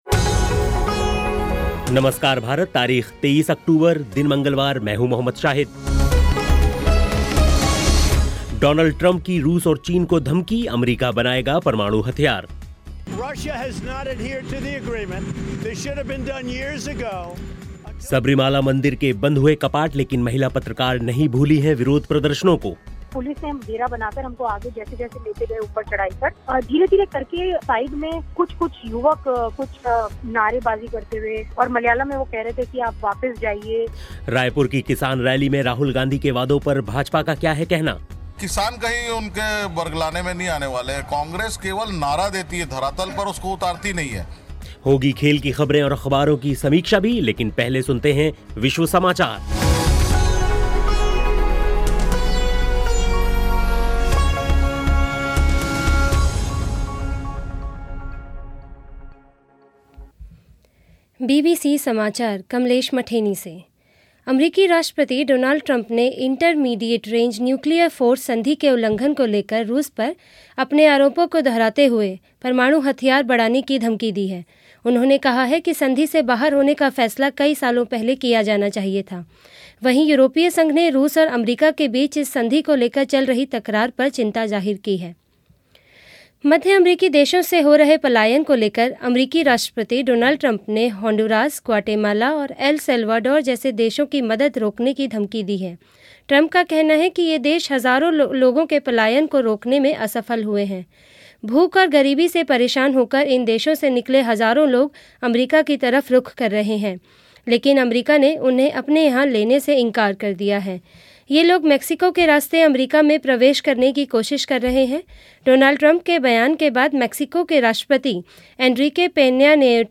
खेल की ख़बरें और अख़बारों की समीक्षा के साथ सुनें विश्व समाचार.